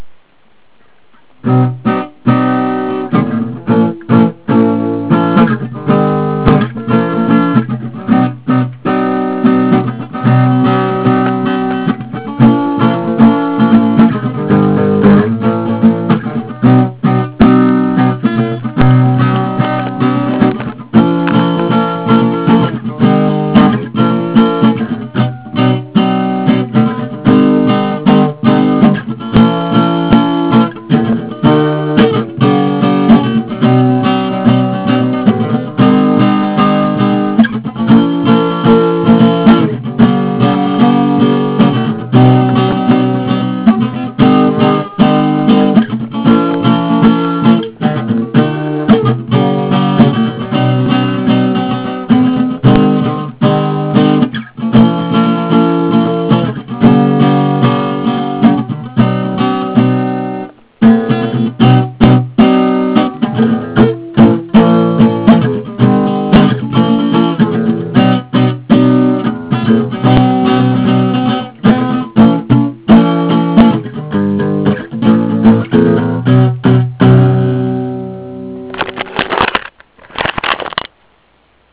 אני קצת מוגבלת-כי אני לא יכולה לשיר, ואני לא יודעת לנגן אקורדים-כך שנותר רק לנגן אקורדים..
הלחן חוזר על עצמו קצת יותר מדי לדעתי,
הלחן לא חוזר על עצמו יותר מדאי, כי אין כ"כ לחן, יש רק ליווי.
מה שכן חסר, ואולי זה מה שהרגשת, זה מלודיה (עוד כלי שמנגן תוים בודדים, או שירה...), נראה לי שתחושת הגיוון תלויה בזה.